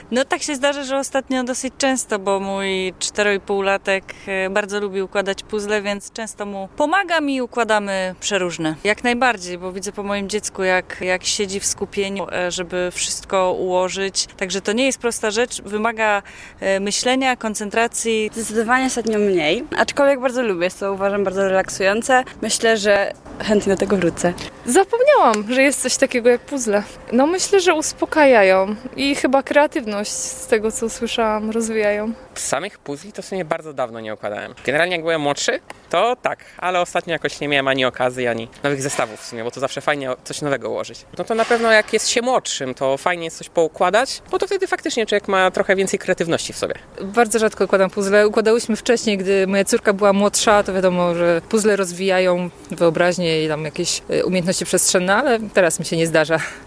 Dziś obchodzimy Międzynarodowy Dzień Puzzli [SONDA]
Zapytaliśmy zielonogórzan, czy zdarza im się układać puzzle oraz czy ich zdaniem mają one wpływ na rozwój człowieka: